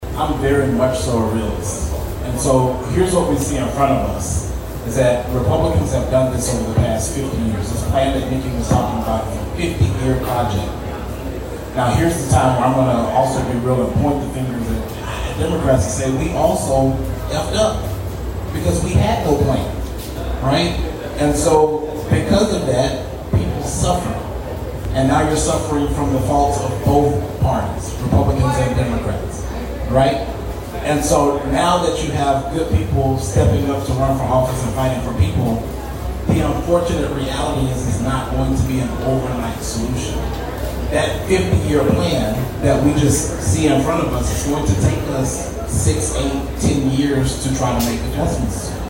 in the town hall the Democrat Party hosted on Saturday